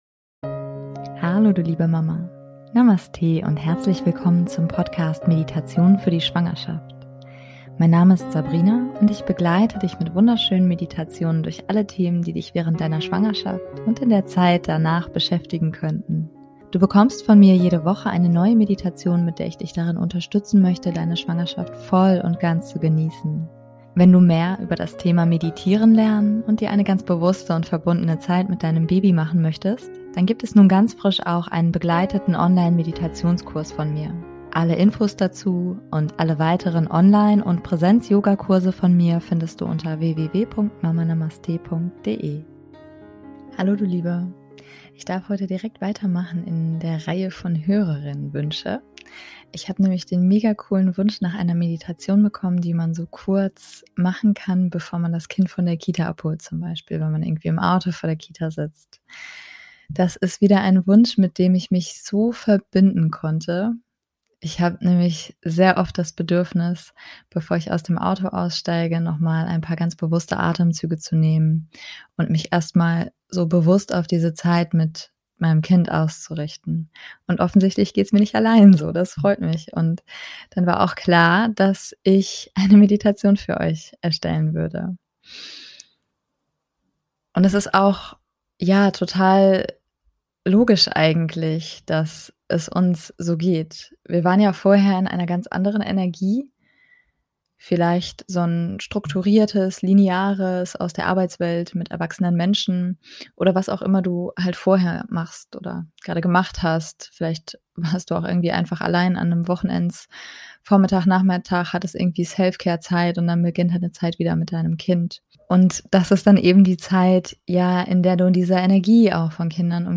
#119 - Kraft tanken bevor die Care Zeit beginnt - Meditation [Für Mamas]